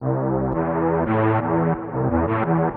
Convoy Strings 04.wav